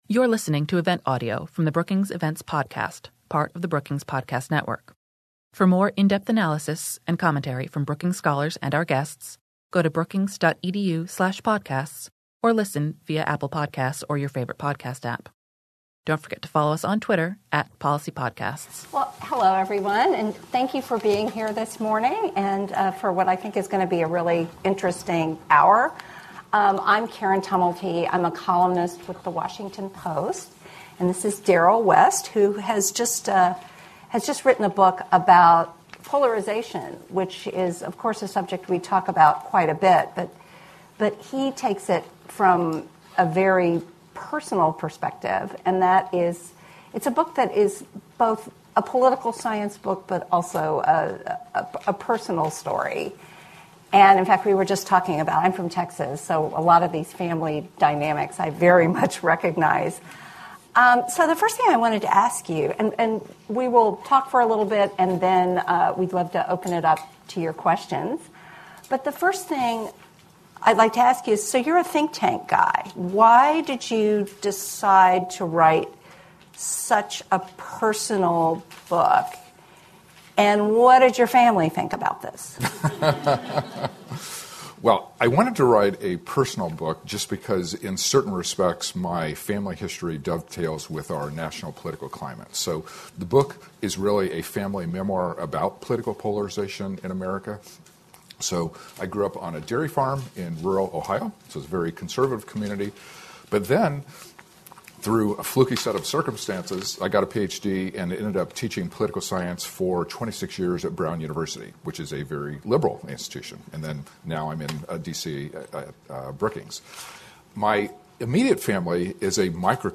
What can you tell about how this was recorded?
Following the conversation, the speakers answered questions from the audience.